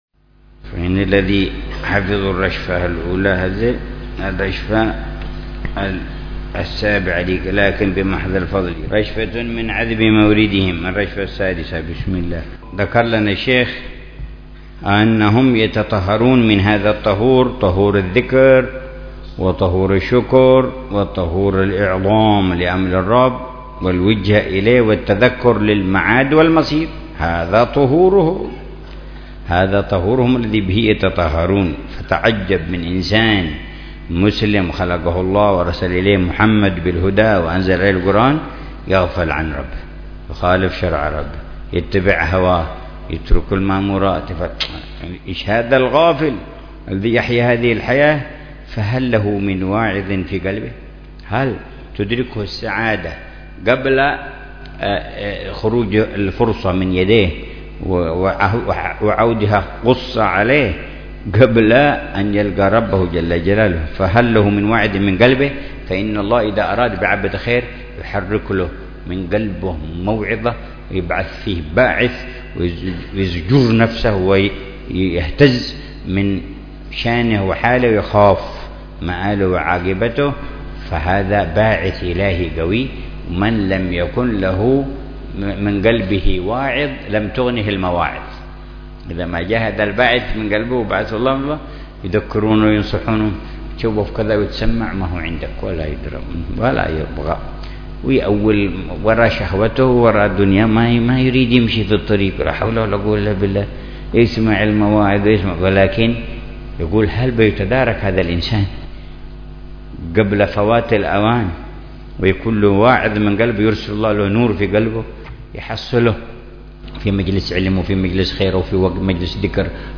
شرح الحبيب عمر بن محمد بن حفيظ لرشفات أهل الكمال ونسمات أهل الوصال.